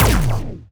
hit_medic.wav